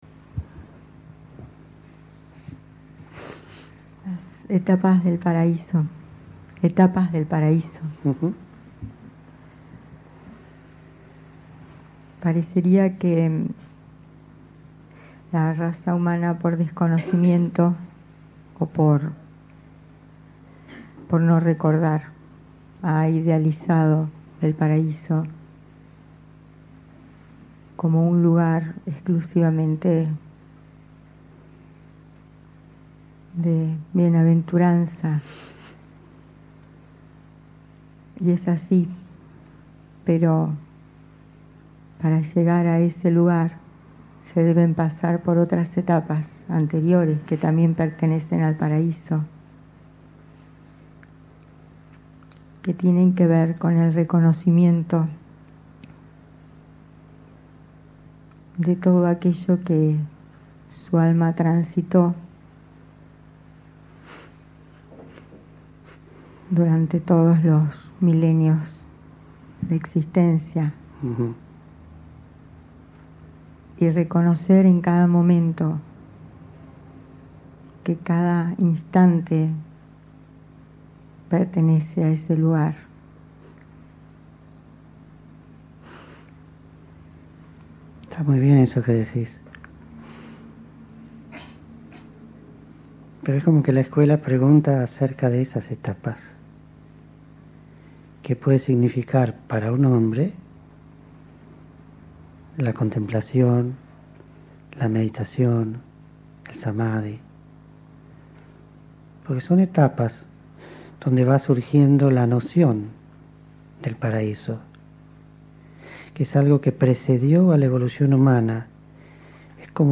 Meditación (Lectura